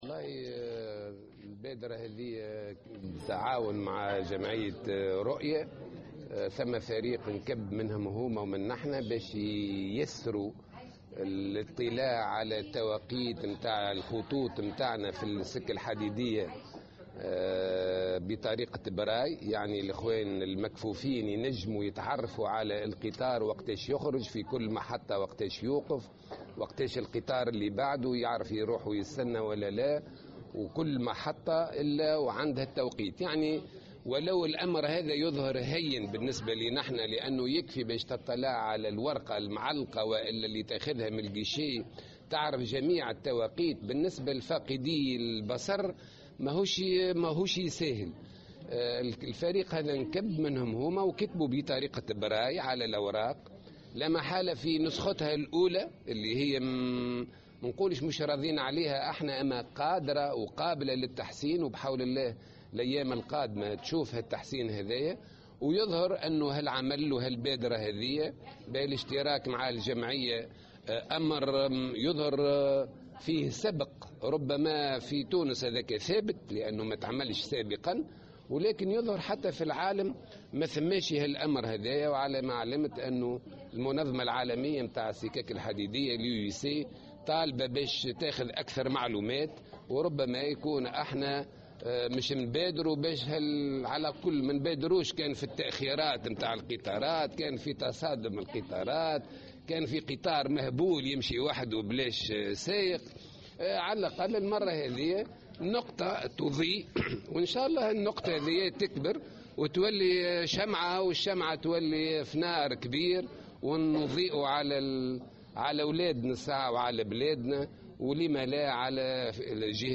في تصريح لمراسلة الجوهرة "اف ام" اليوم الأربعاء خلال يوم اعلامي